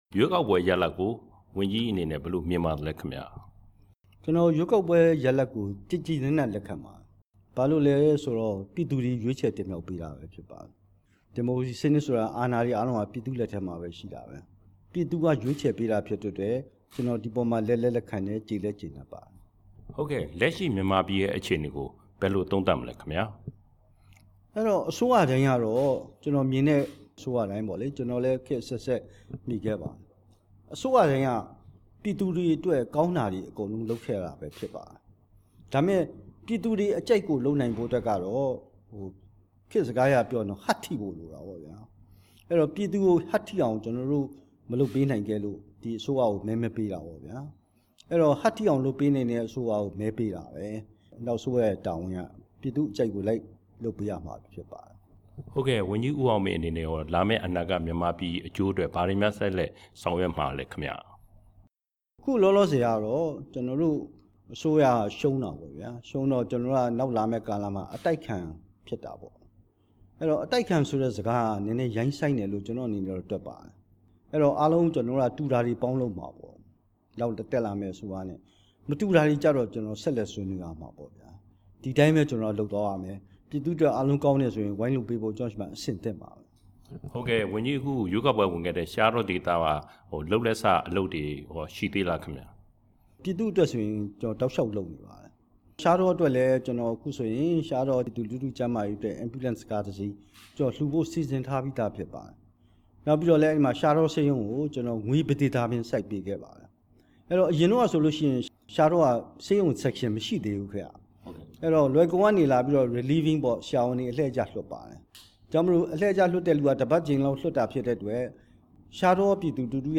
ရွေးကောက်ပွဲရလဒ် ဝန်ကြီး ဦးအောင်မင်းနဲ့ မေးမြန်းချက်